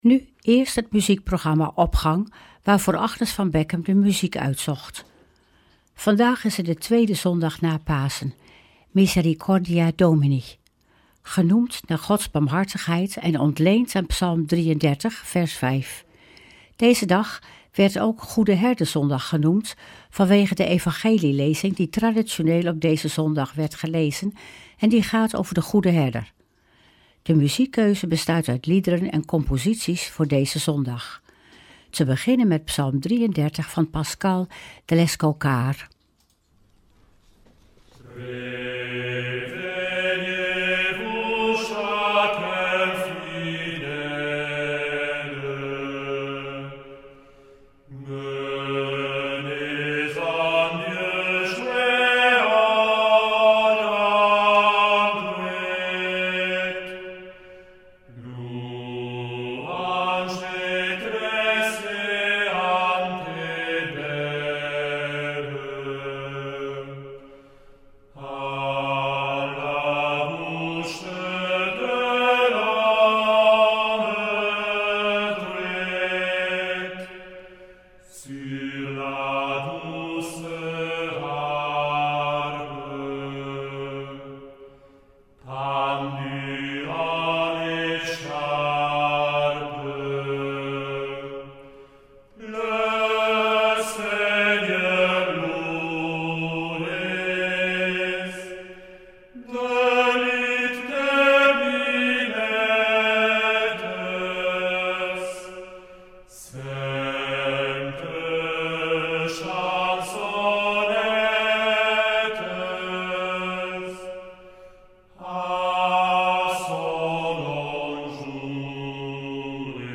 Opening van deze zondag met muziek, rechtstreeks vanuit onze studio.
In deze lezing gaat het over de goede Herder. De muziekkeuze bestaat uit liederen en composities voor deze zondag.